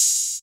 open hat - southside.wav